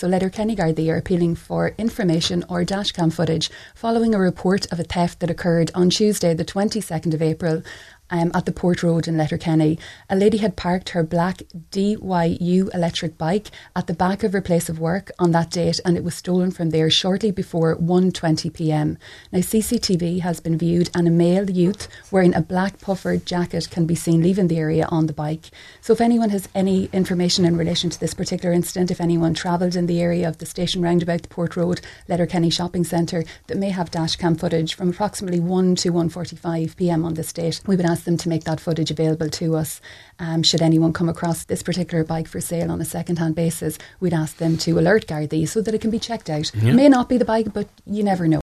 made this appeal for information on this morning’s Nine ‘til Noon Show: